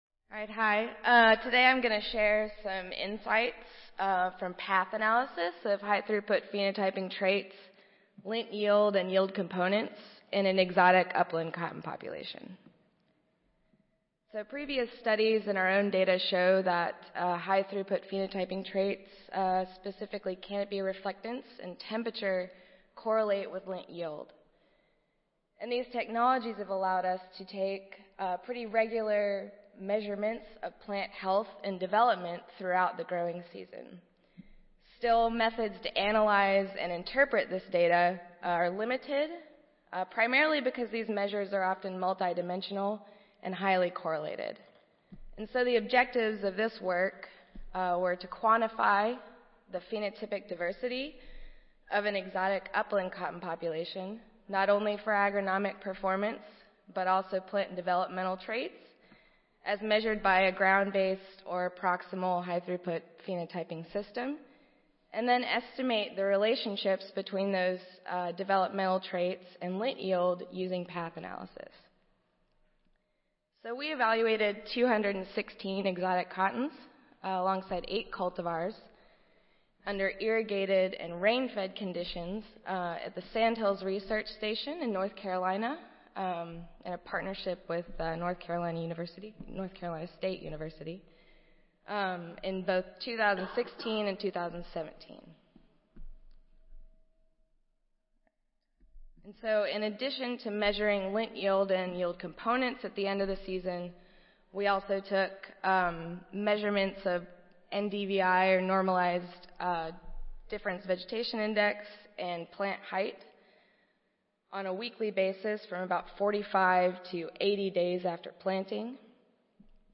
Lightning talks tied to a poster
Audio File Recorded Presentation